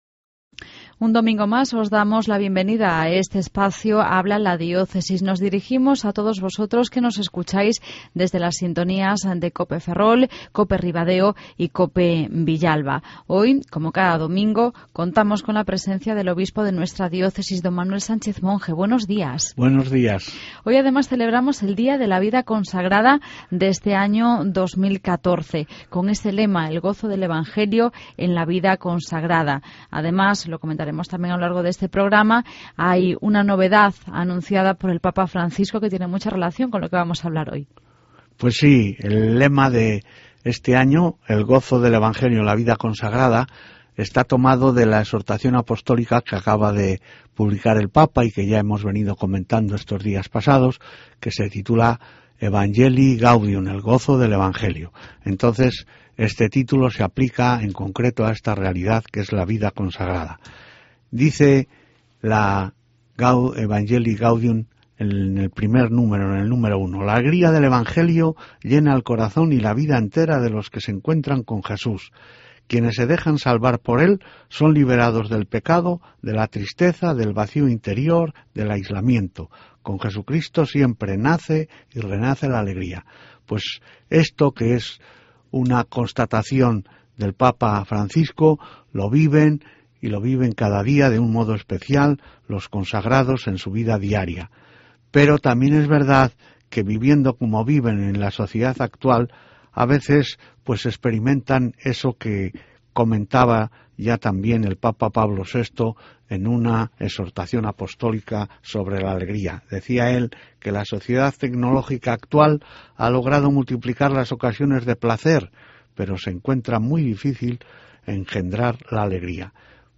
El obispo de nuestra Diócesis, D. Manuel Sánchez Monge, nos habla del lema del día de la Vida Consagrada: El gozo del Evangelio en la Vida Consagrada.